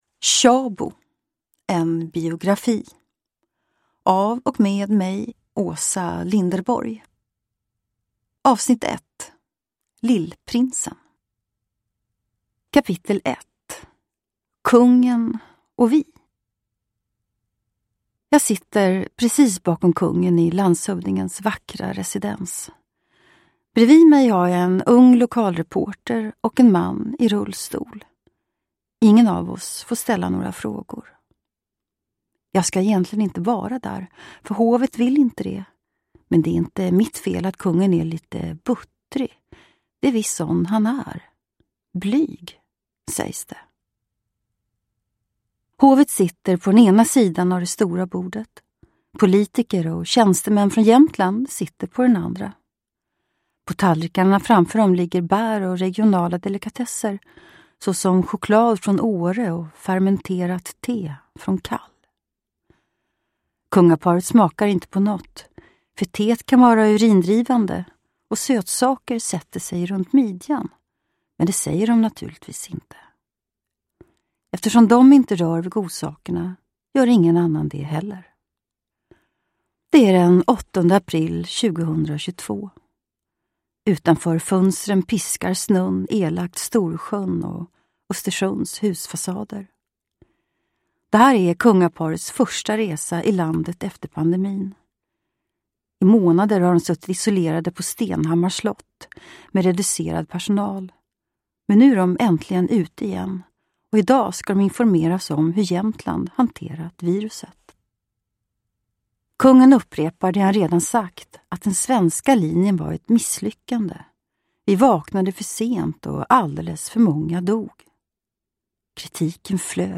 Uppläsare: Åsa Linderborg
Ljudbok